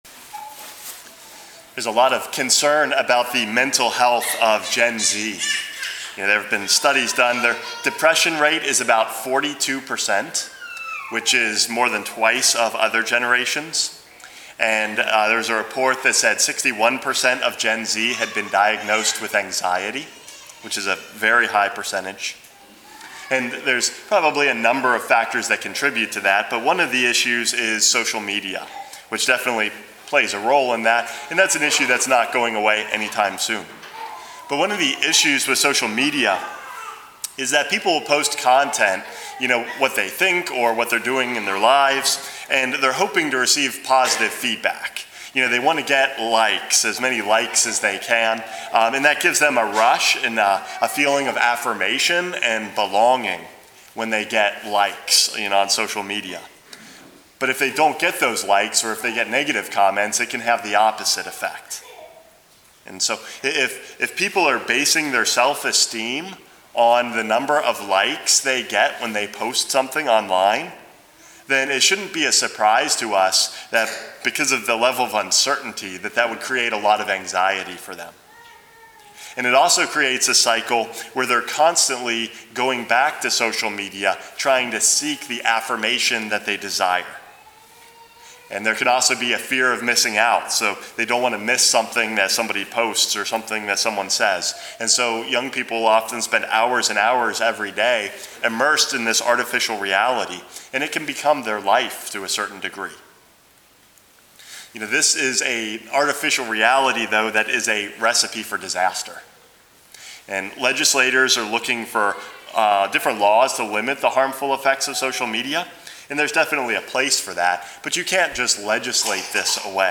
Homily #435 - The Voice of the Father